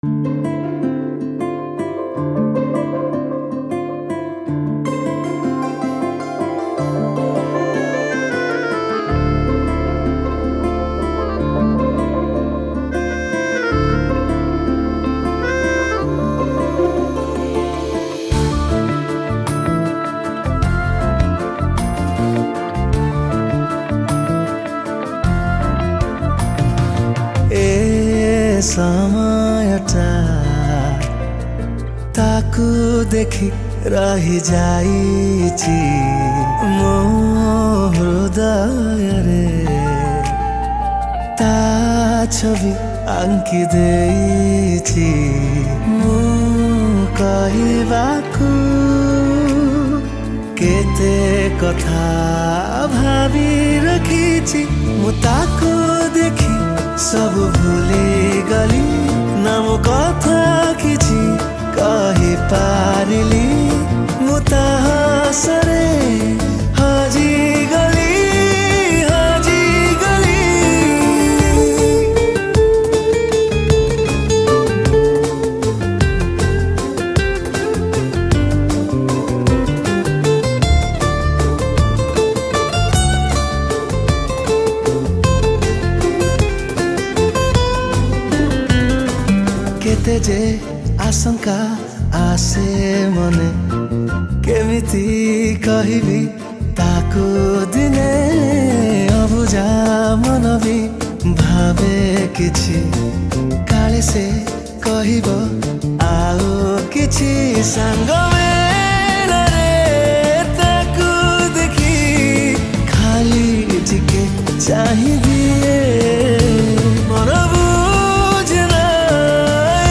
Keyboard Programming
Guitar
Rhythm Programming